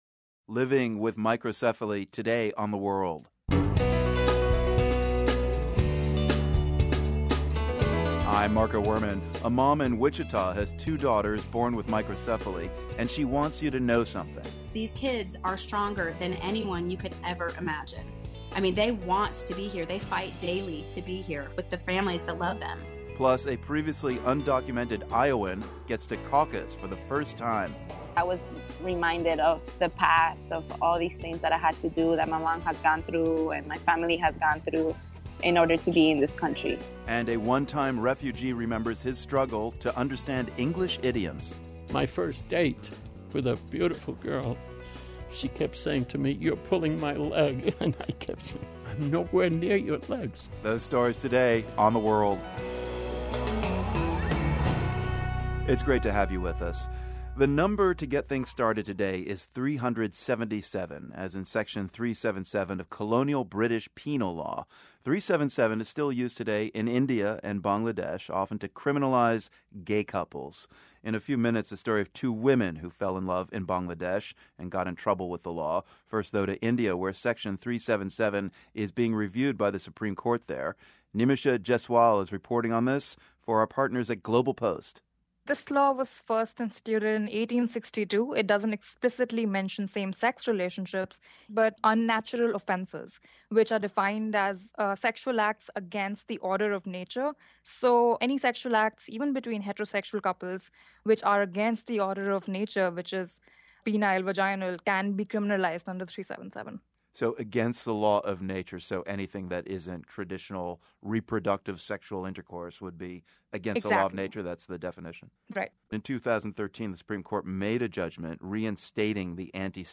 Plus, a refugee camp in Jordan is struggling to cope with recent arrivals from Syria. Also, host Marco Werman has a conversation with Yanni.